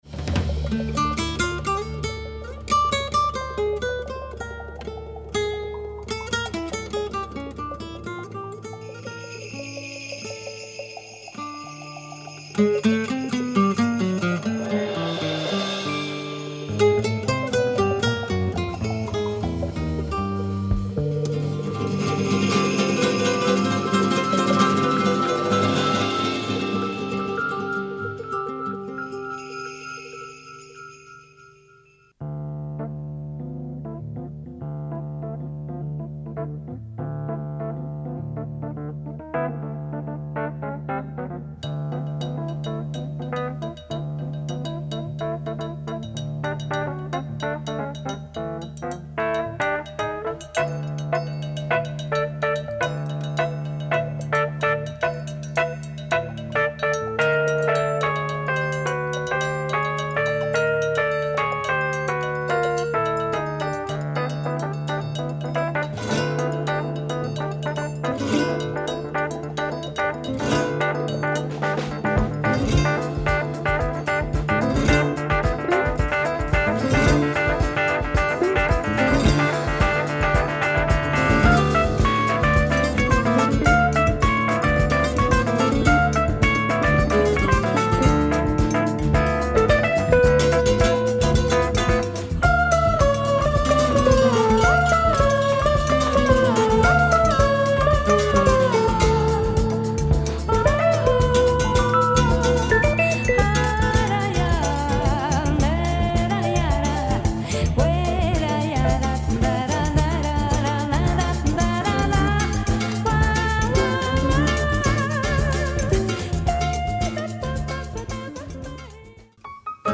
flamenco samba jazz
superb wordless vocals
Splendid prog jazz folk album from Catalunya !